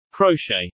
Crochet